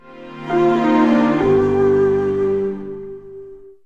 Windows ME Shutdown.wav